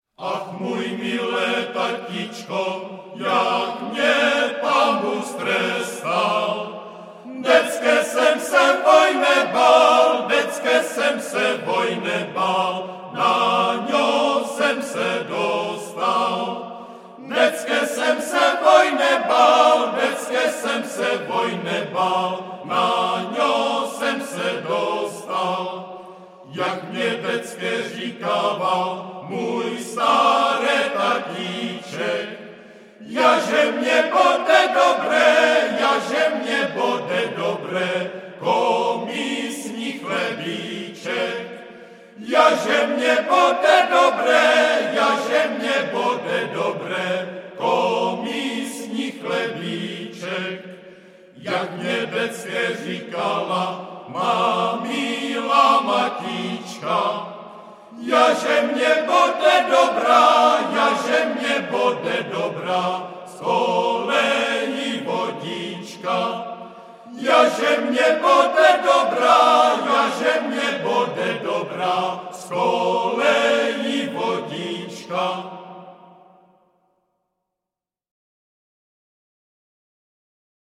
Žánr: World music/Ethno/Folk
písní a capella